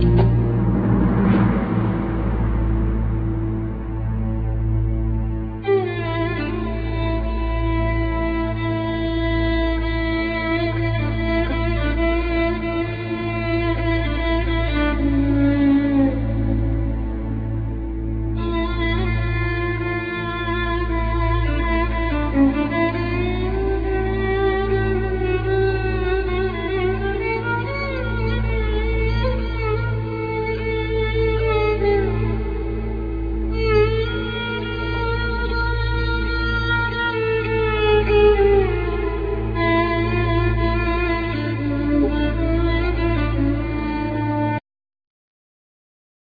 Banjo,Guitars,Piano
Double Bass
Vocals
Violin
Accordion
Drums